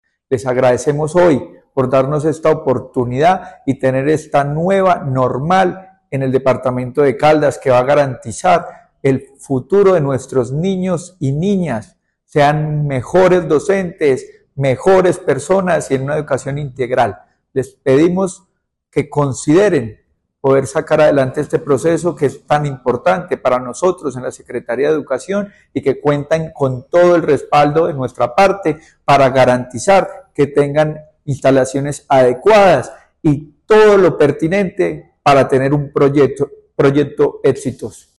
Luis Herney Vargas Barrera, secretario de Educación de Caldas
Secretario-de-Educacion-Luis-Herney-Vargas-IE-Marco-Fidel-Suarez-a-normal.mp3